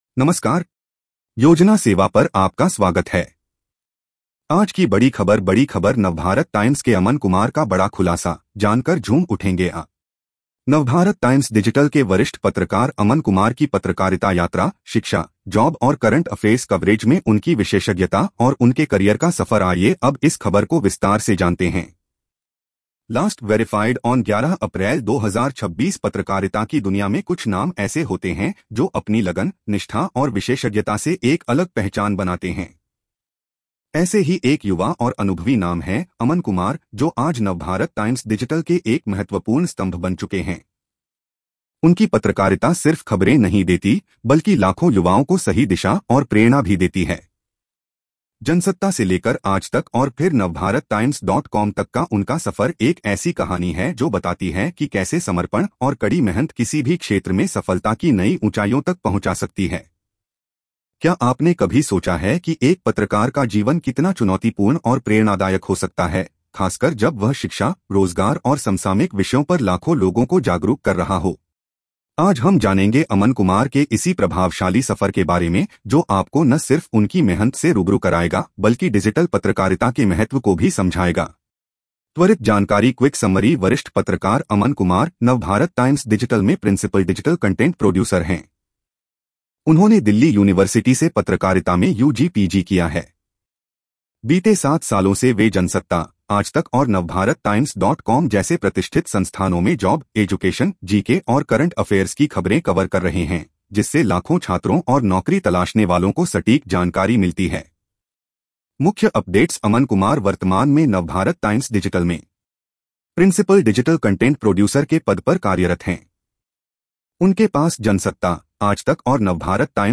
News Audio Summary
🎧 इस खबर को सुनें (AI Audio):